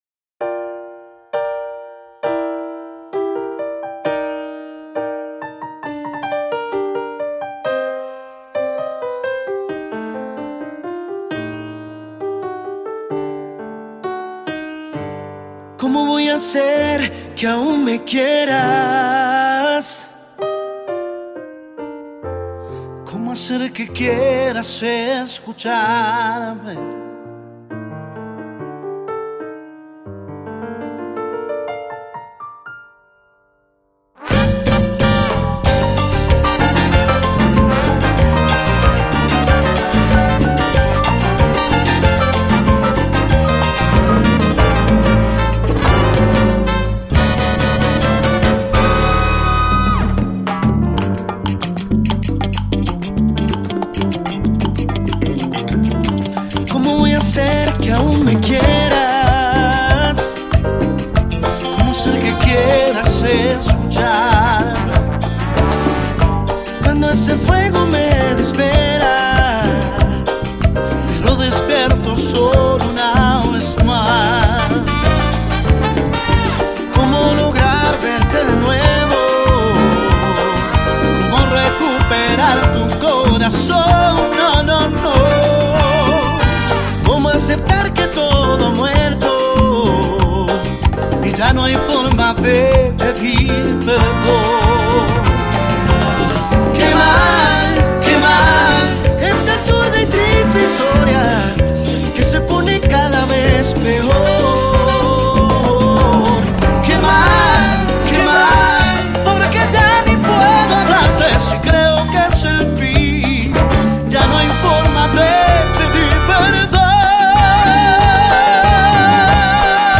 Genre Cumbia